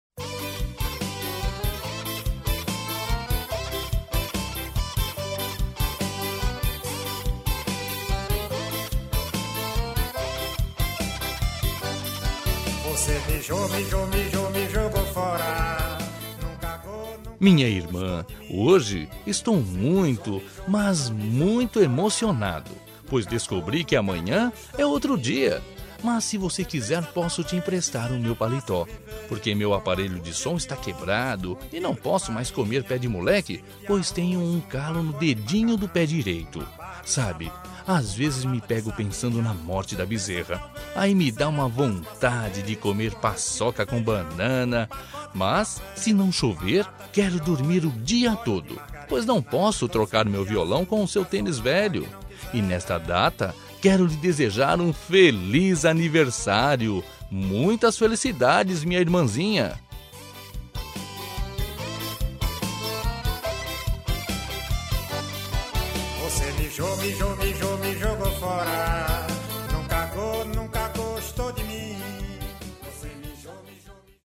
Aniversário de Humor – Voz Masculina – Cód: 200209